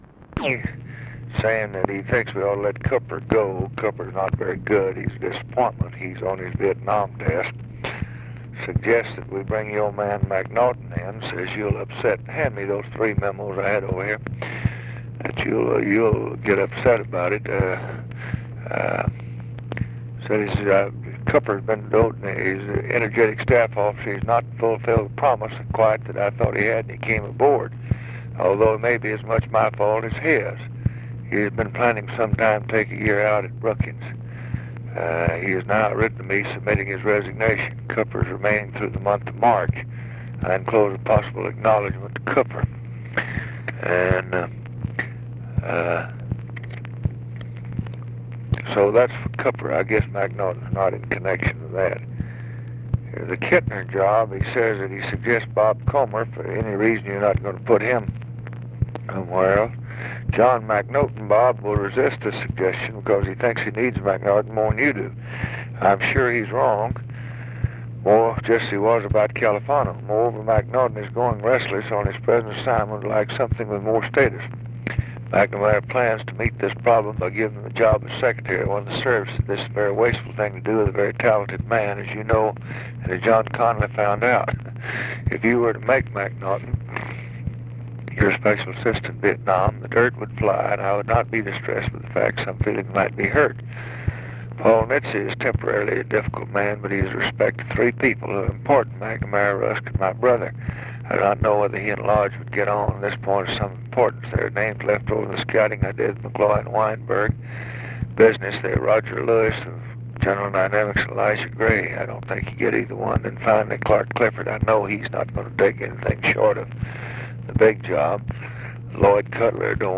Conversation with ROBERT MCNAMARA, February 28, 1966
Secret White House Tapes